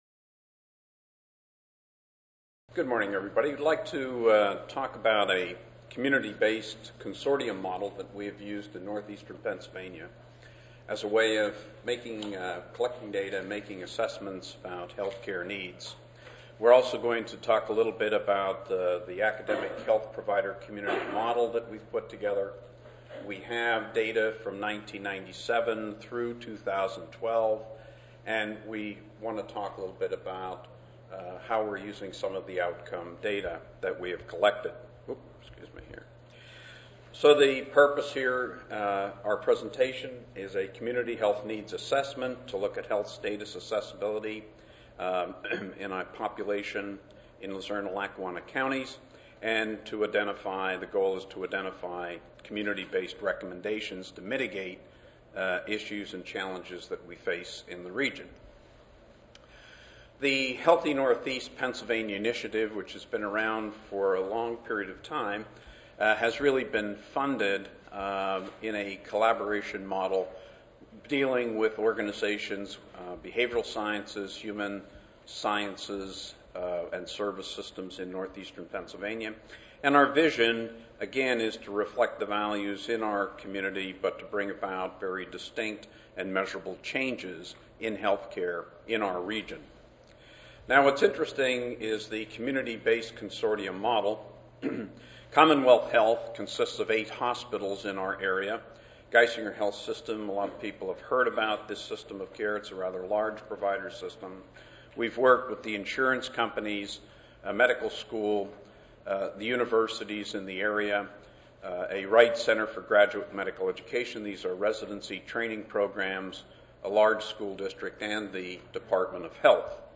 5074.0 Building Community Capacity for Health Improvement: Collaborative and Integrated Approaches Wednesday, November 6, 2013: 8:30 a.m. - 10:00 a.m. Oral The purpose of this session is to provide examples of successful strategies to engage the community in research and practices to improve and sustain health.